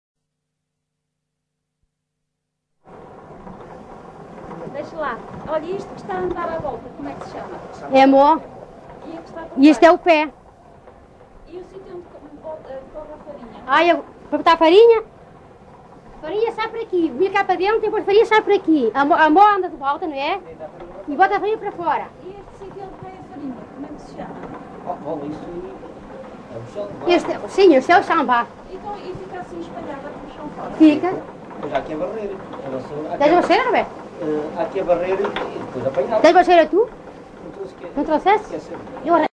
LocalidadeBade (Valença, Viana do Castelo)